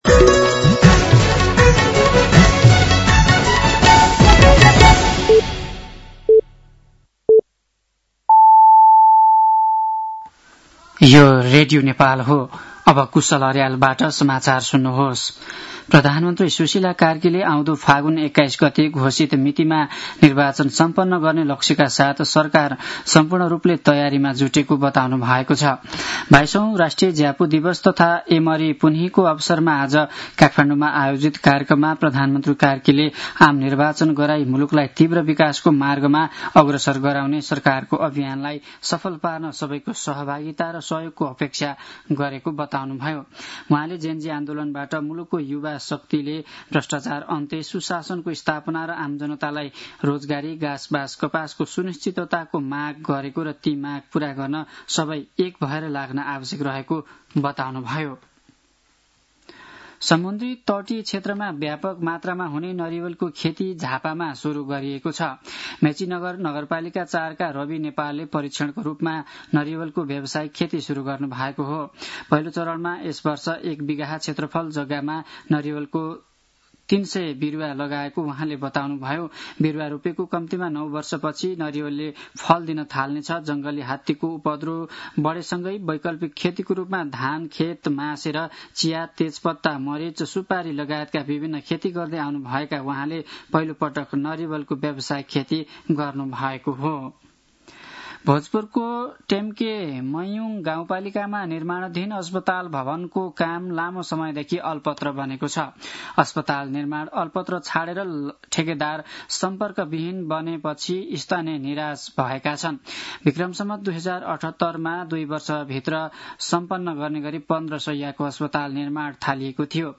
साँझ ५ बजेको नेपाली समाचार : १८ मंसिर , २०८२
5.-pm-nepali-news-.mp3